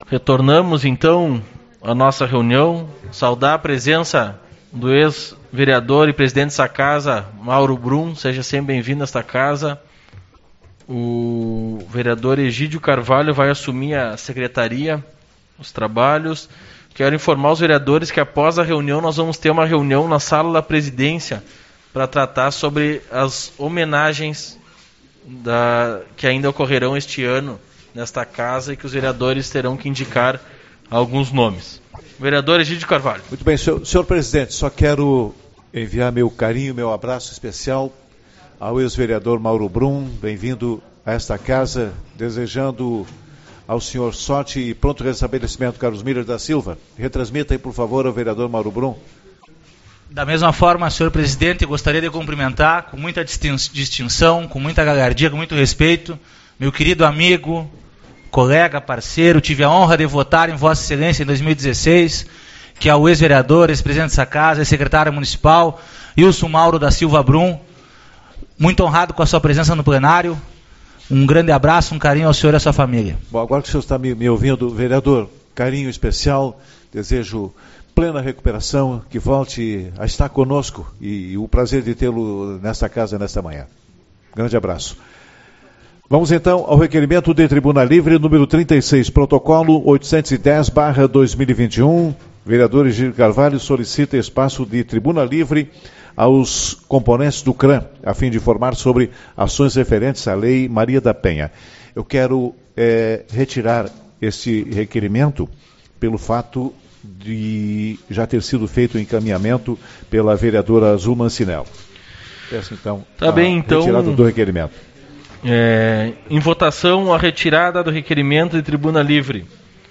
17/08 - Reunião Ordinária